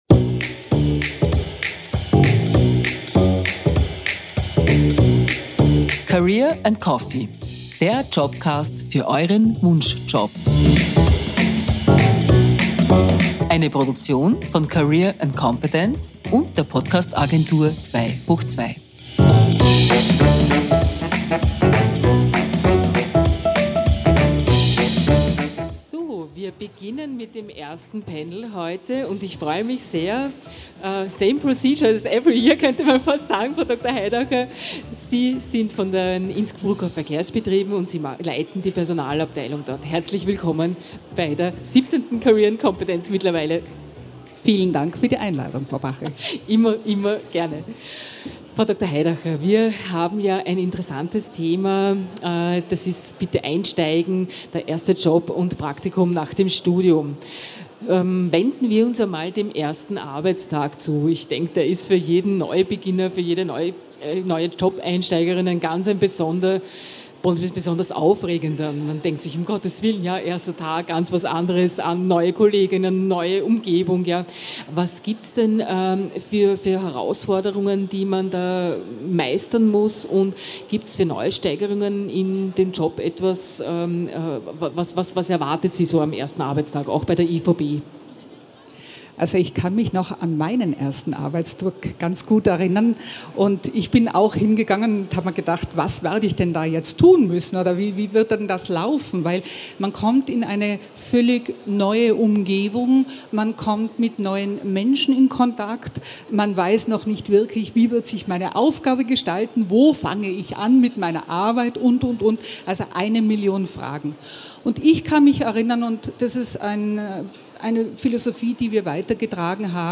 Livemitschnitt von der career & competence 2023 in Innsbruck, am 26. April 2023.